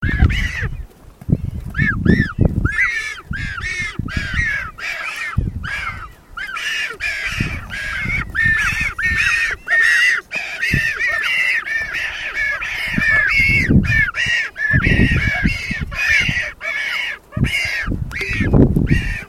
Pingüino Patagónico (Spheniscus magellanicus)
Nombre en inglés: Magellanic Penguin
Fase de la vida: Varios
Localidad o área protegida: Área Natural Protegida Punta Tombo
Condición: Silvestre
Certeza: Fotografiada, Vocalización Grabada
pinguinos.mp3